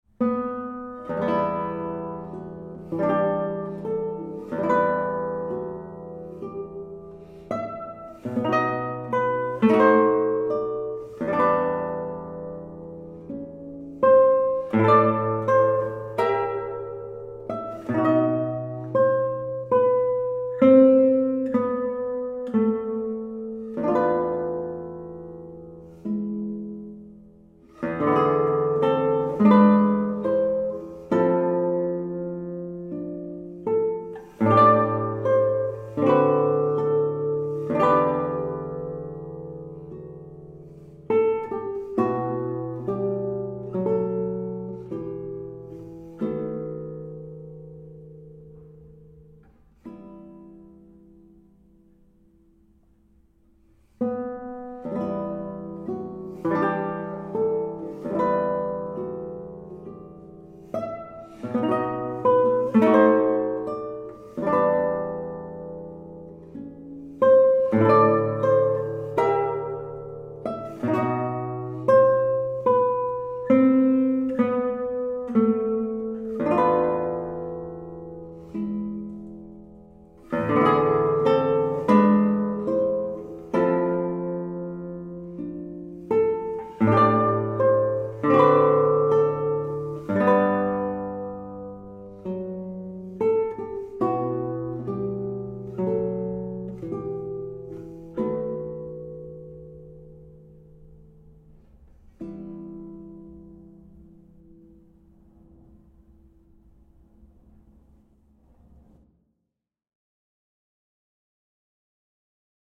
Aquí se puede escuchar la muestra de audio de El canto de los pájaros (El cant dels ocells), una canción popular catalana. Transcripción para guitarra